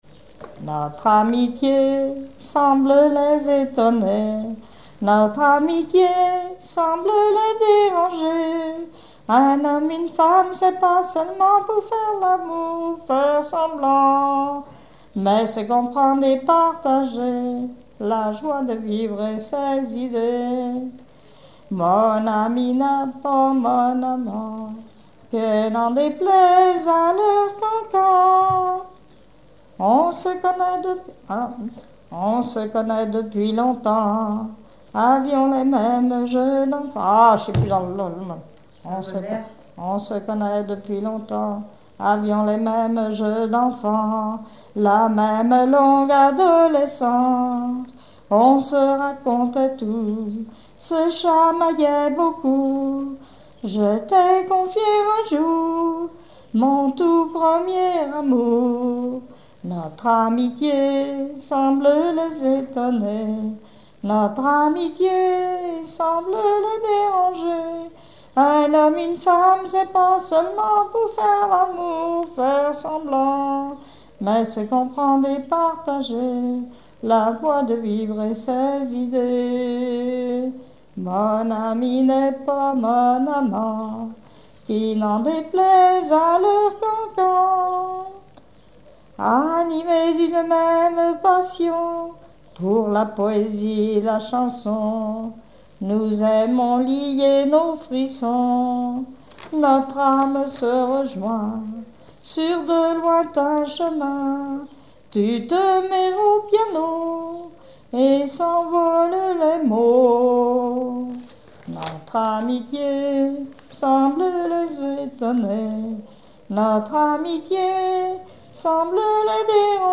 Genre strophique
Chansons locales et traditionnelles
Pièce musicale inédite